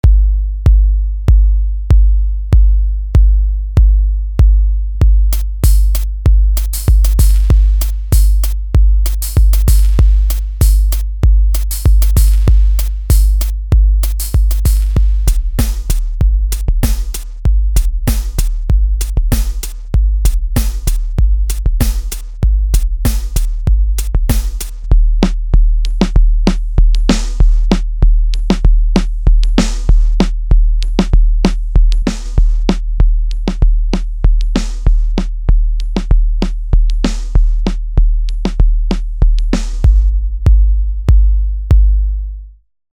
А вот еще офигительная забытая драм машина MFB Tanzbar 2 , которая уже не выпускается , мне кажется она самая оптимальная на сей день по аналогу ... Вложения MFB Tanzbär2 D1 4 Pattern.mp3 MFB Tanzbär2 D1 4 Pattern.mp3 1,6 MB · Просмотры: 807 MFB Tanzbär2 D2.mp3 MFB Tanzbär2 D2.mp3 1,9 MB · Просмотры: 794